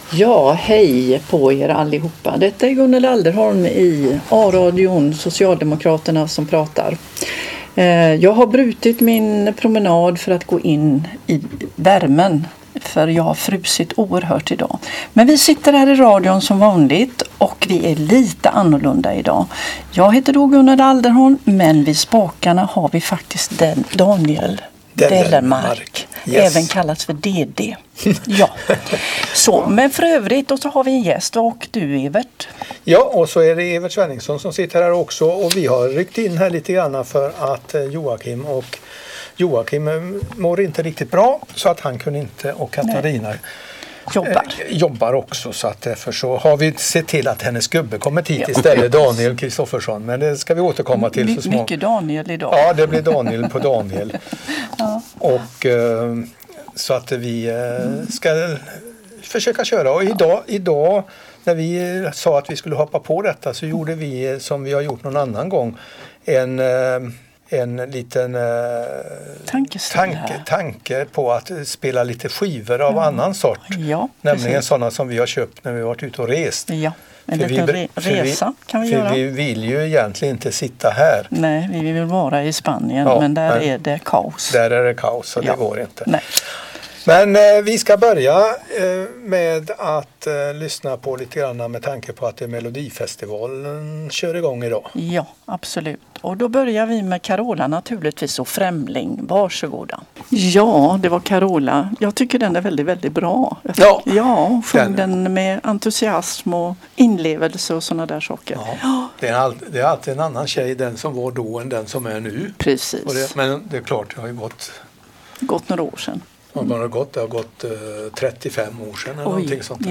Musiken är bortklippt av upphovsrättsliga skäl.